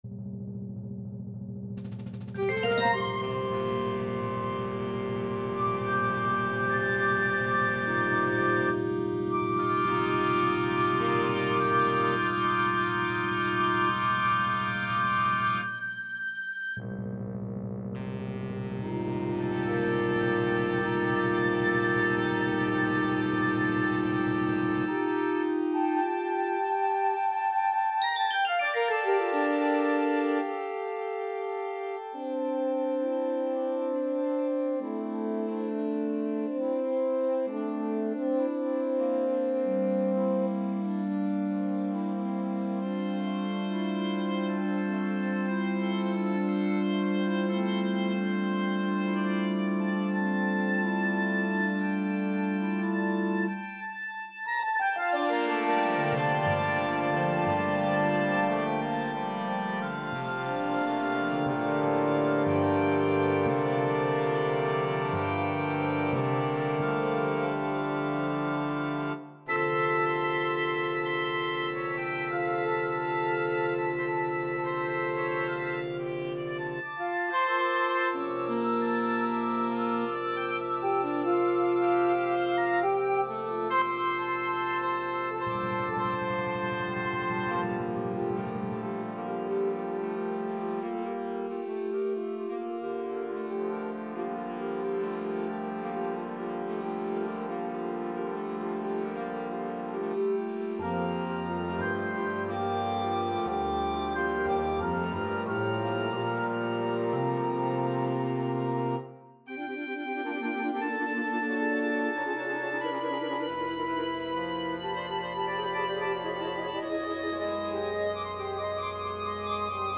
2018      wind ensemble      Duration: 7:30